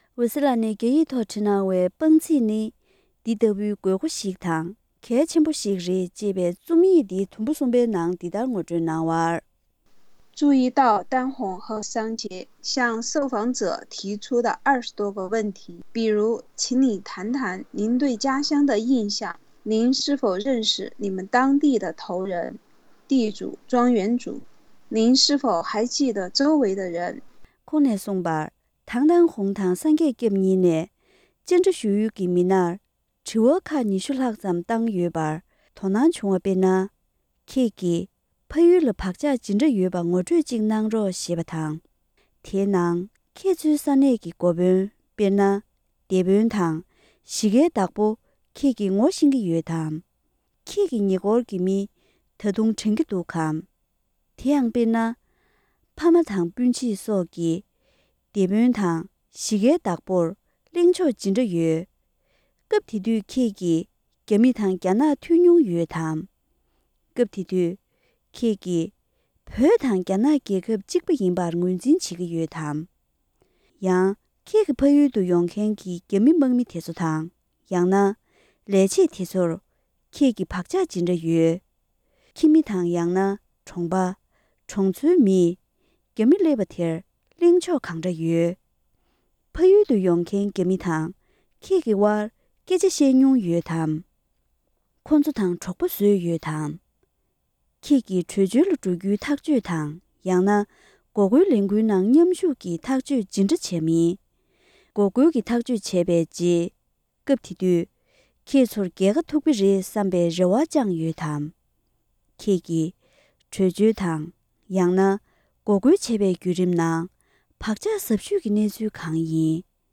ཕབ་བསྒྱུར་སྙན་སྒྲོན་གནང་གི་རེད།།